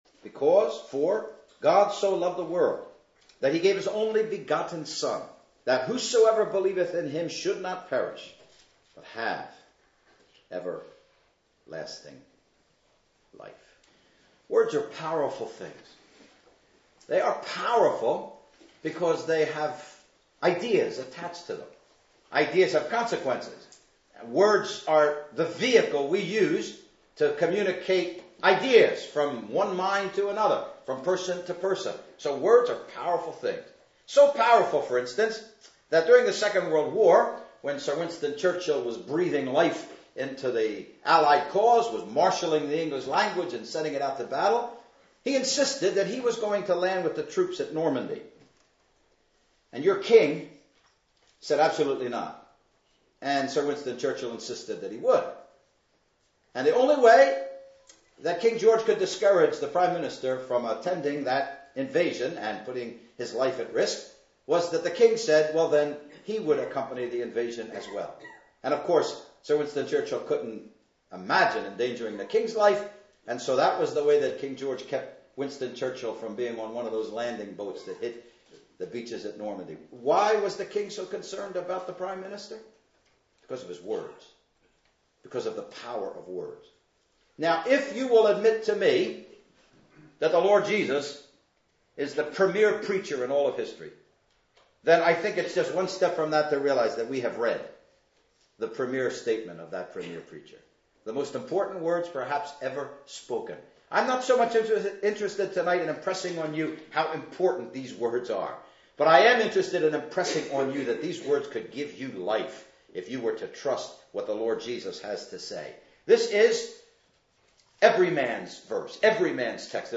(Message preached 9th Nov 2008 in Ambrosden Village Hall)